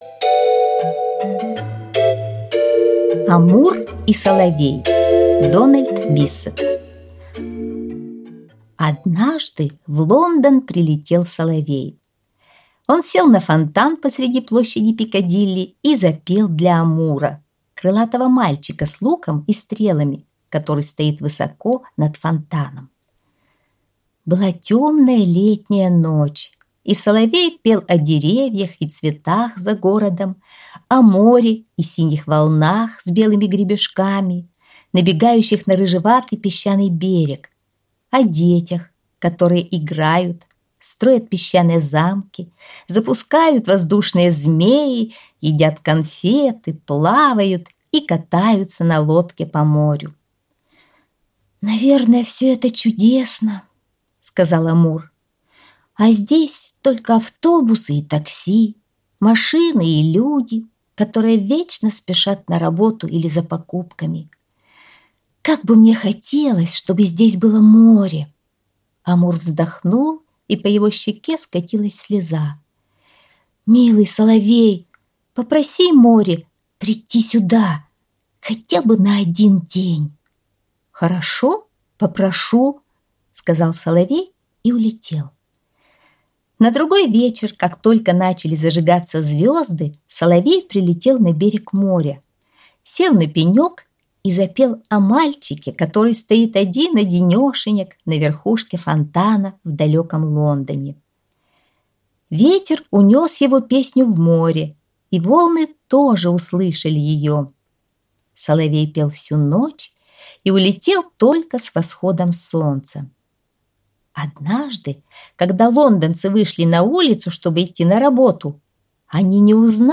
Аудиосказка «Амур и соловей»